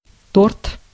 Ääntäminen
Ääntäminen Tuntematon aksentti: IPA: /tort/ Haettu sana löytyi näillä lähdekielillä: venäjä Käännös Konteksti Ääninäyte Substantiivit 1. cake US UK 2. gateau brittienglanti Translitterointi: tort.